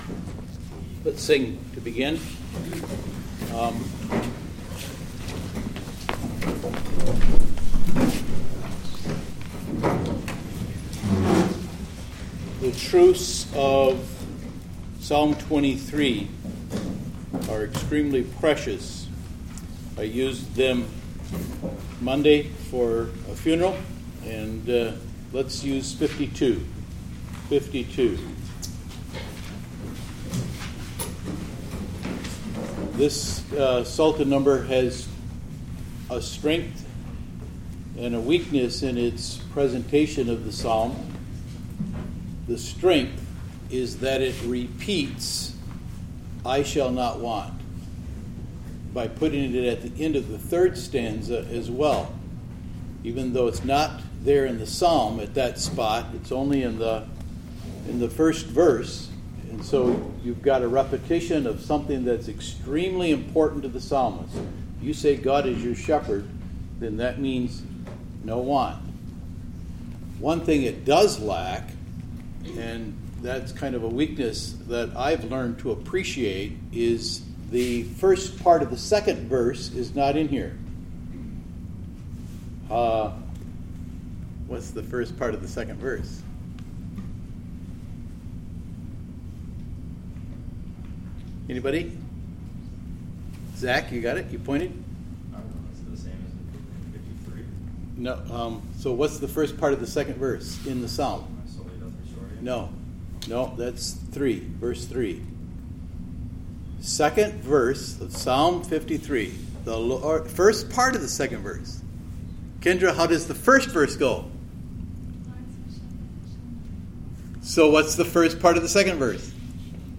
Saturday we had our first Talking Points event.
We did record it and we would like to offer that audio now, so that those who could not make it can still enjoy the speech.